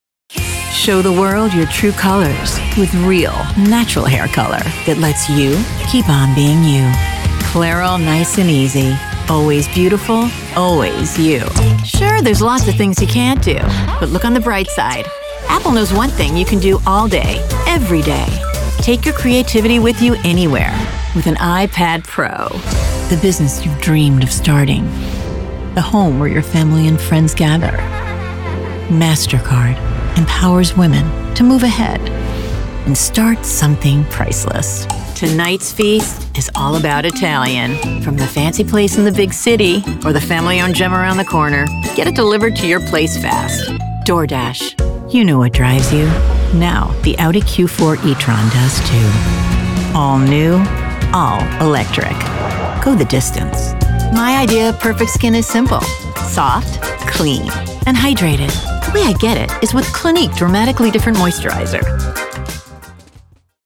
Voiceover Artist,
Sex: Female
Ages Performed: Young Adult, Middle Age,
Sennheiser MKH 416 Microphone, Neumann TLM 103 Microphone, Audient ID14 Interface